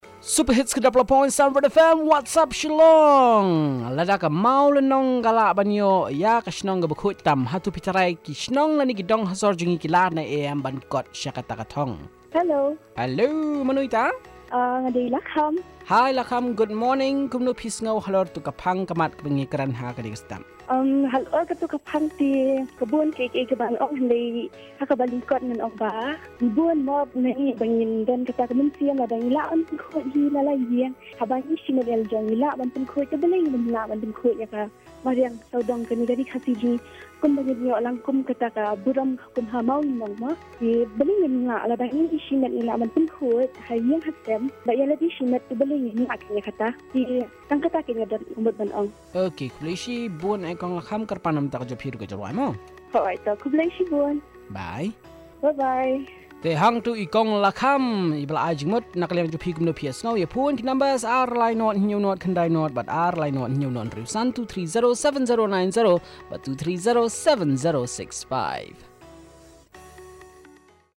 Caller 1 on Shillong following the footsteps of Mawlynnnong